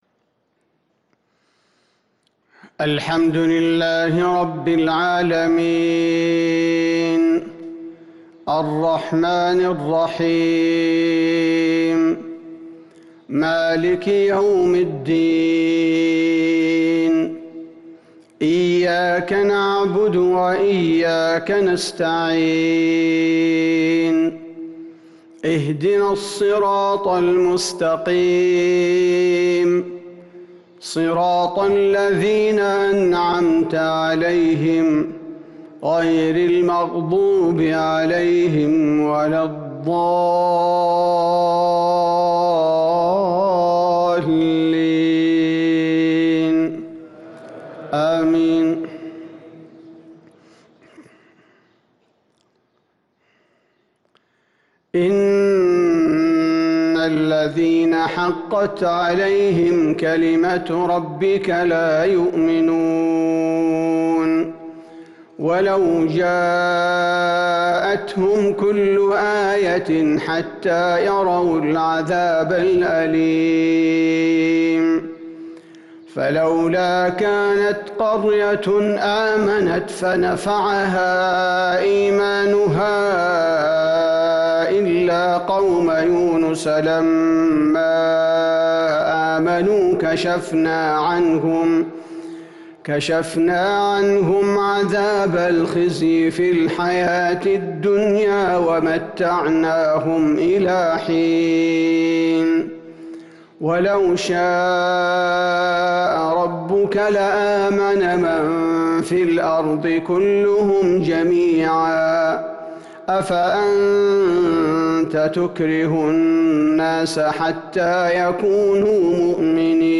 فجر ٧-٧- ١٤٤٣هـ سورة يونس | Fajr prayer from Surah Yunus 8-2-2022 > 1443 🕌 > الفروض - تلاوات الحرمين